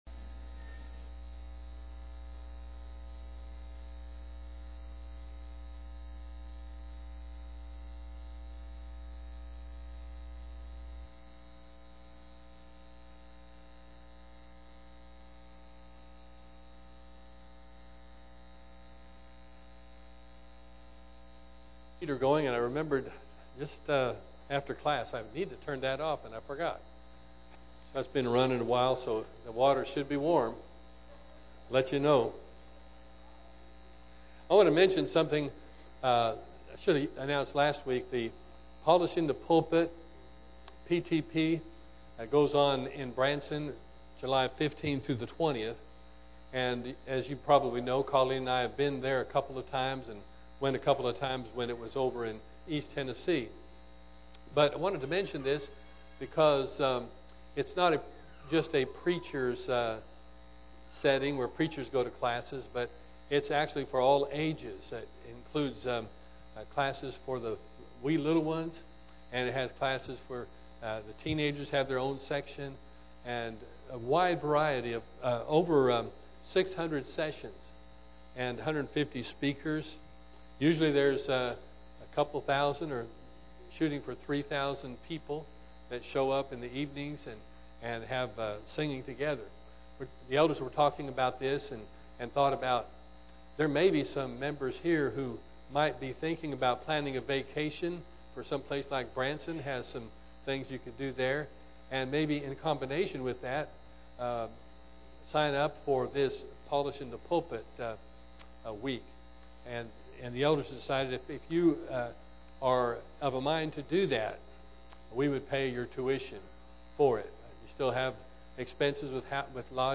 Greenbrier church of Christ